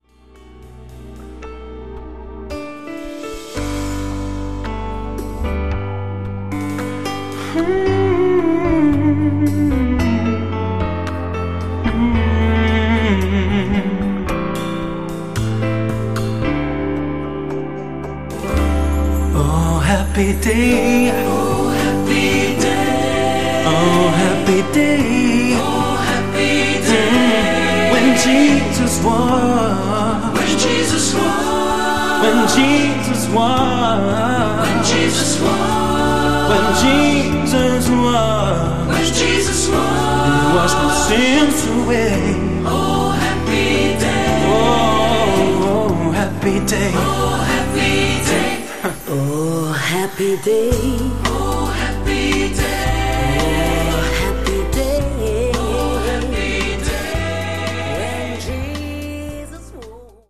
tenore
contralto
soprano
pianista arrangiatore
Drums
Un lavoro ben riuscito che va ascoltato anche al di là dello stretto rapporto che lo lega alle feste, perché i 4 Heaven & More hanno spogliato questi brani degli arrangiamenti scontati che da sempre li hanno contraddistinti per ricavarne delle versioni certamente più autentiche allo spirito per il quale erano state scritte e lo hanno fatto reinventandole in chiave jazz e soul.